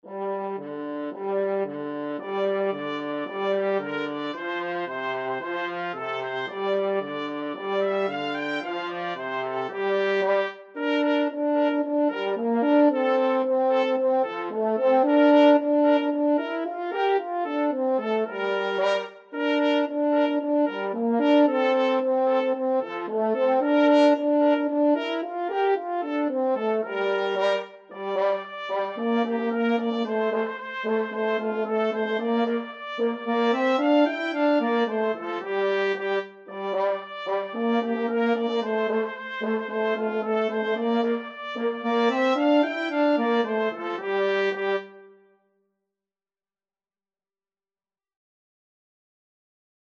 Allegro moderato = c. 112 (View more music marked Allegro)
2/4 (View more 2/4 Music)